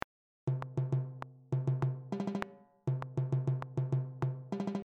FishMarket_timbales.mp3